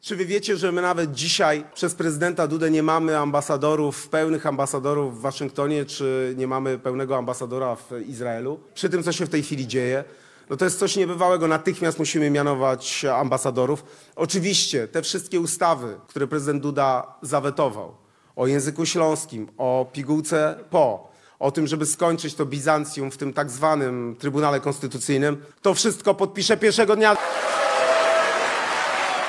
Kandydat na prezydenta RP Rafał Trzaskowski, podczas wczorajszego spotkania w Teatrze Polskim w Szczecinie, przedstawił swoje stanowisko w kilku kluczowych kwestiach dotyczących polityki krajowej.